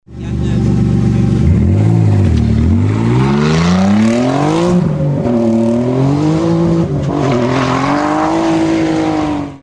Sons de moteurs maserati - Engine sounds maserati - bruit V8 V10 maserati